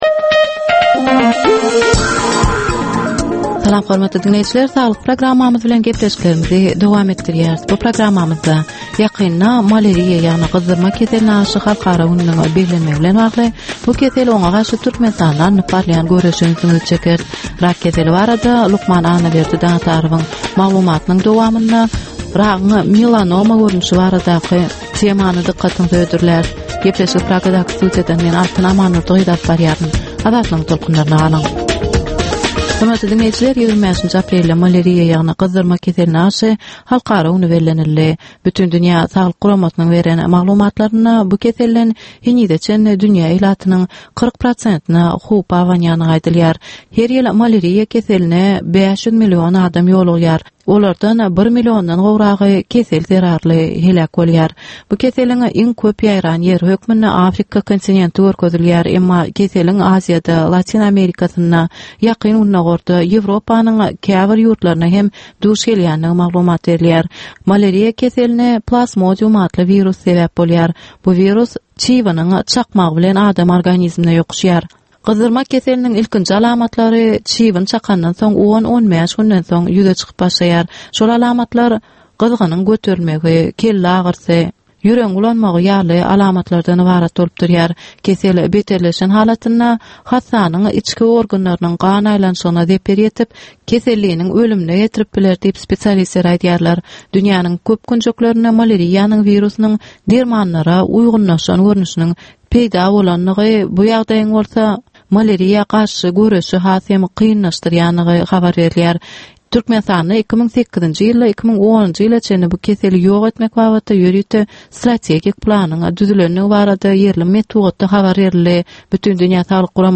Ynsan saglygyny gorap saklamak bilen baglanyşykly maglumatlar, täzelikler, wakalar, meseleler, problemalar we çözgütler barada 10 minutlyk ýörite gepleşik.